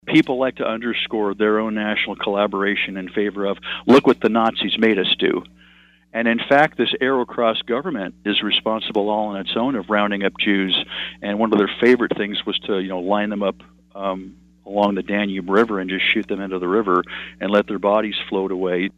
KSAL Morning News Extra